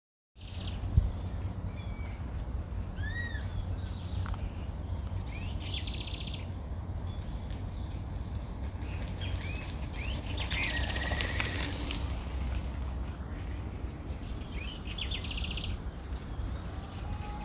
Burlisto Pico Canela (Myiarchus swainsoni)
Nombre en inglés: Swainson´s Flycatcher
Localidad o área protegida: Reserva Ecológica Costanera Sur (RECS)
Condición: Silvestre
Certeza: Fotografiada, Vocalización Grabada